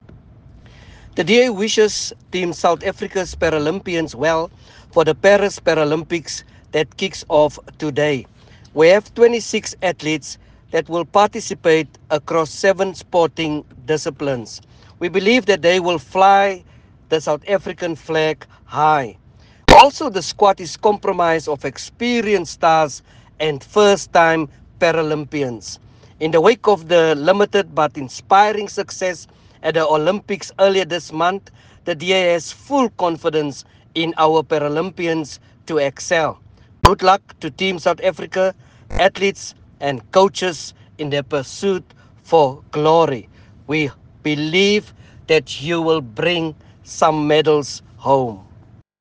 soundbite by Joe McGluwa MP